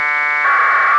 PACKET AX-25 /ISS/ Запись в формате 96кгц * 16 бит